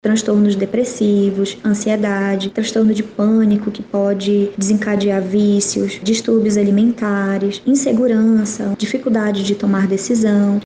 A especialista ressalta que os abusos geram consequências graves que podem perdurar na fase adulta.